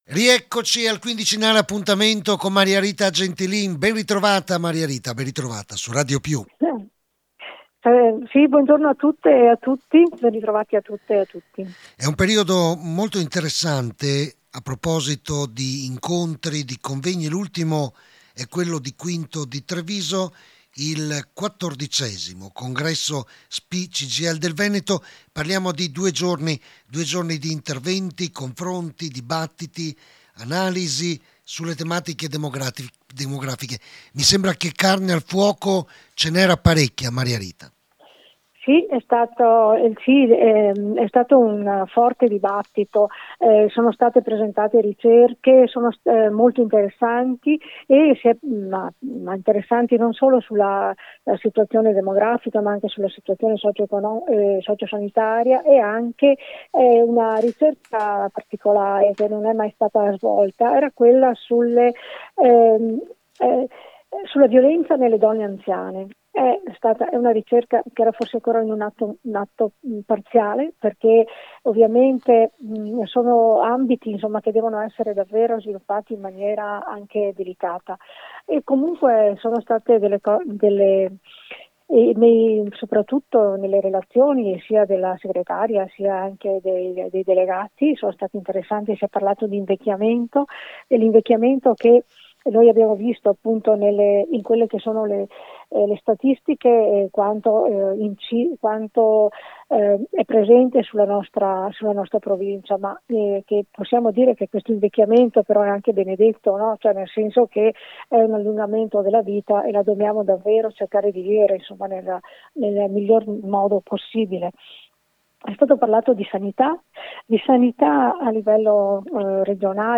DAL CONVEGNO DI QUINTO, AI MICROFONI DI RADIOPIU